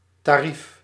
Ääntäminen
IPA : /ɹeɪt/ US : IPA : [ɹeɪt]